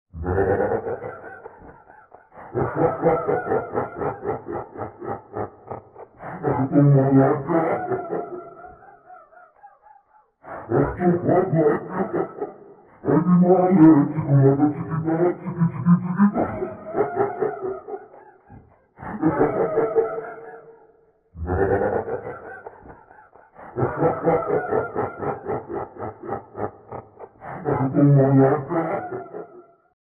Risada